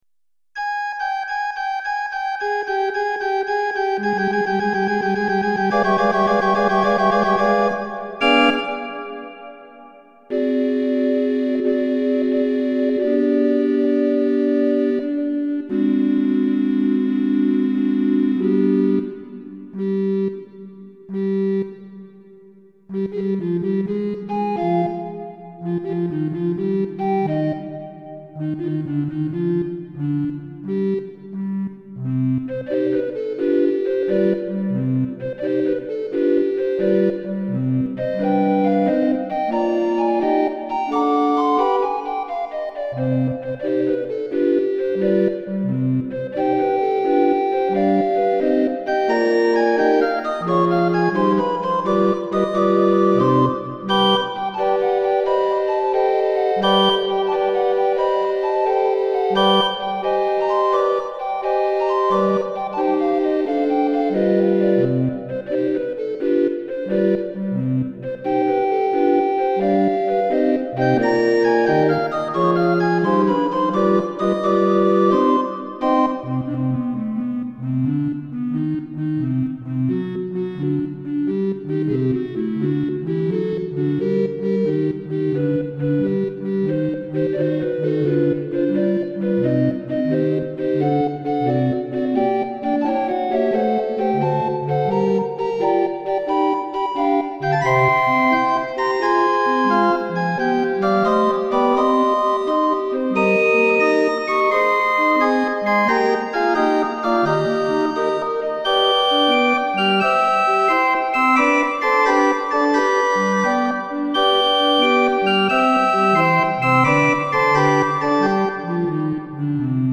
Wilcox & White Organ Roll Audio